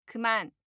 알림음(효과음) + 벨소리
알림음 8_그만2-여자.mp3